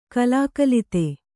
♪ kalākalite